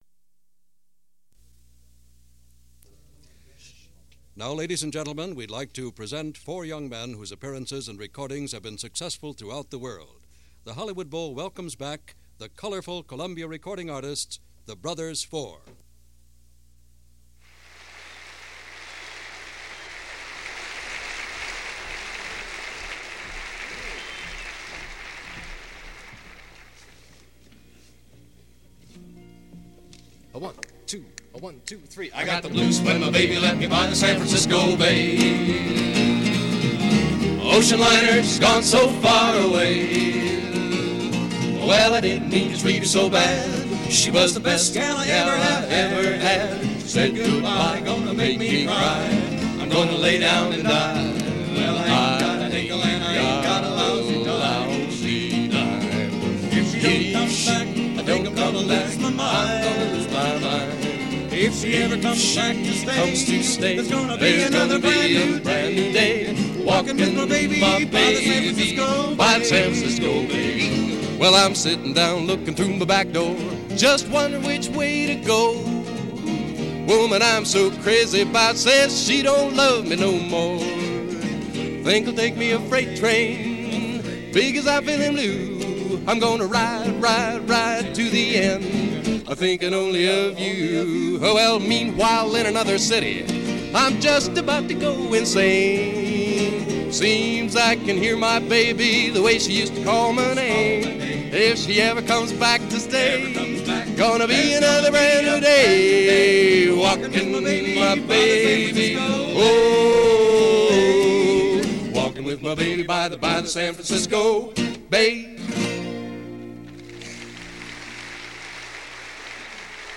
Live At The Hollywood Bowl
Folk Night At The Bowl
Tonight it’s back to the Hollywood Bowl for Folk Night 1965.